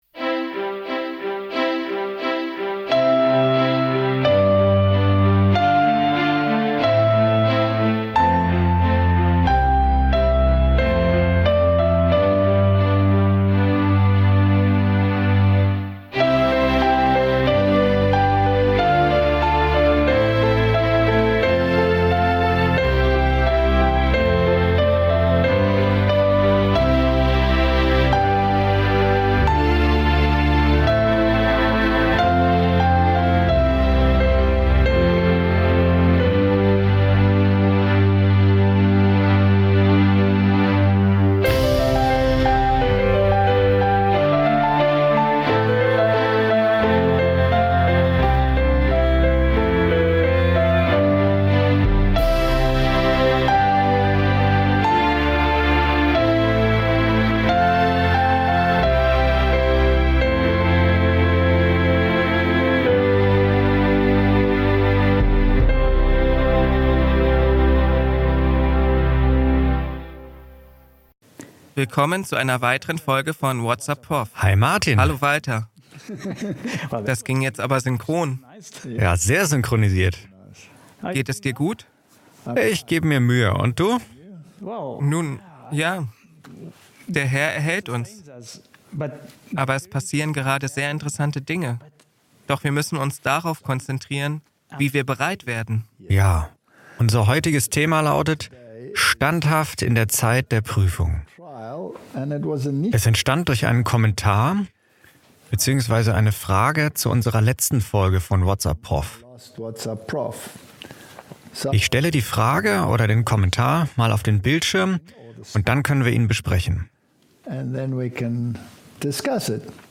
spricht in einem Dialog über das aktuelle Zeitgeschehen und den Bezug zur biblischen Prophetie.